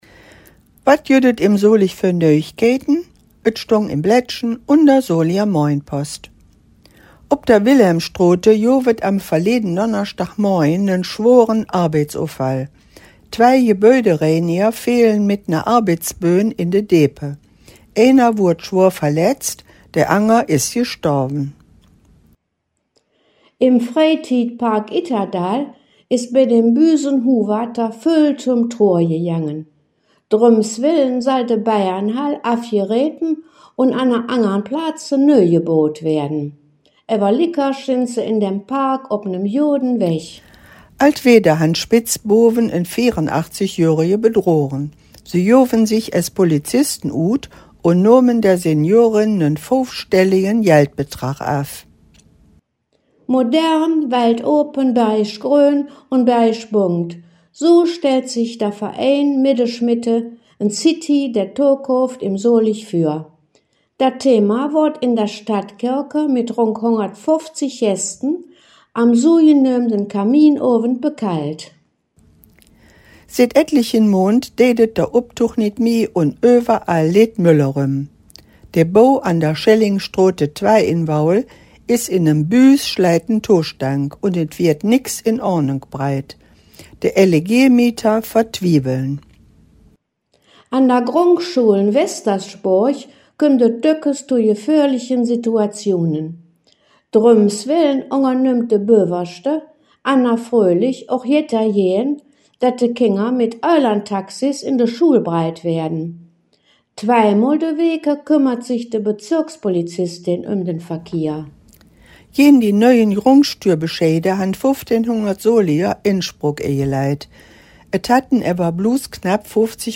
Solinger Platt Nachrichten – Dös Weeke em Solig (25/14)